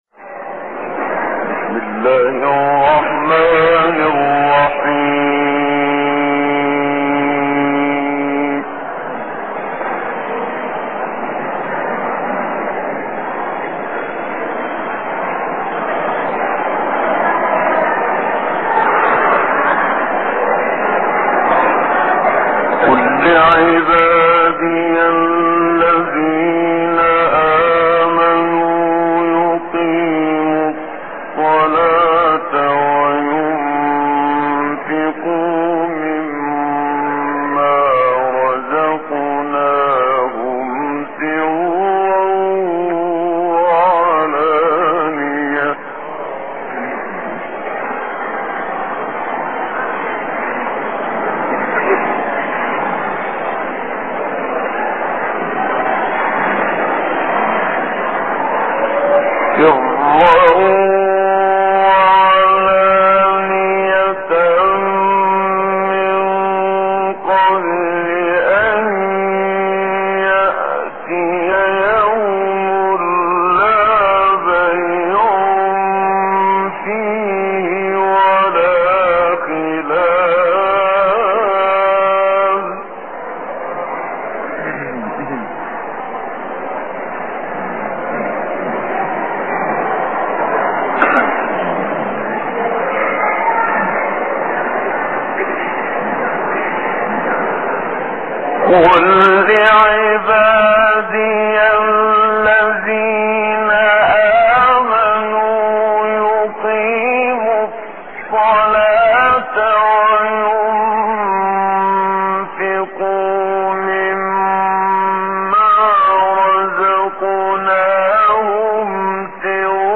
تلاوت آیاتی از سوره ابراهیم توسط استاد محمد صدیق منشاوی + دانلود mp3
تهران - الکوثر : در ادامه تلاوت آیات 31 تا 41 سوره ابراهیم را با صدای مرحوم محمدصدیق منشاوی می شنوید.